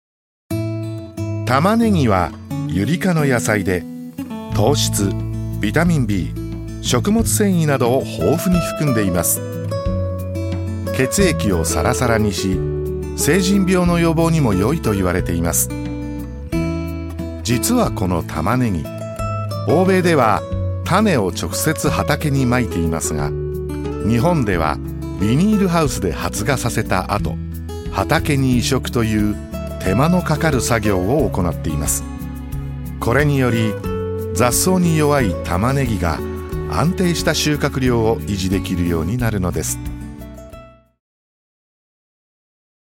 ● ボイスサンプル07